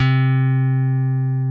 C3 2 F.BASS.wav